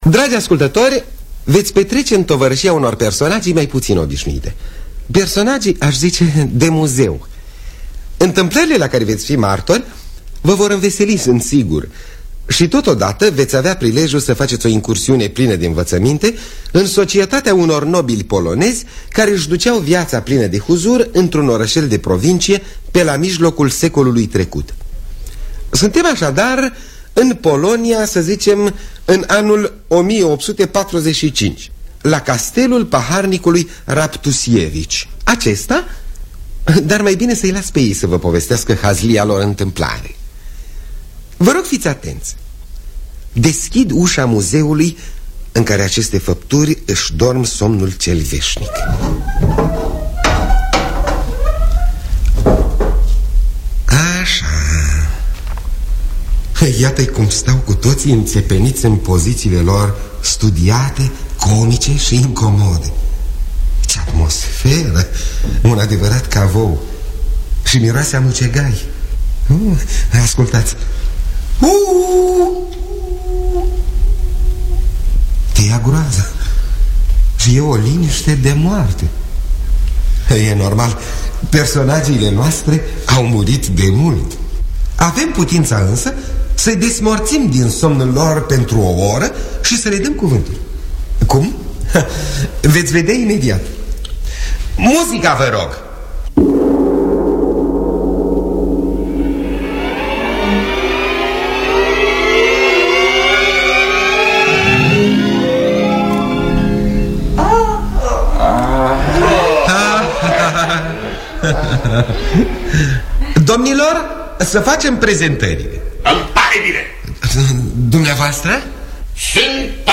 “Răzbunarea” de Aleksander Fredro – Teatru Radiofonic Online